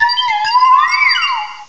cry_not_glaceon.aif